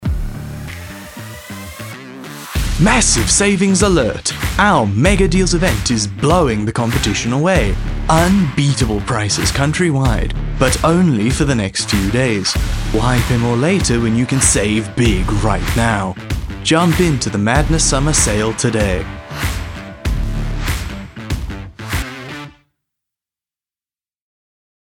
animation, articulate, authoritative, character, Deep
Hard Sell- Summer Sale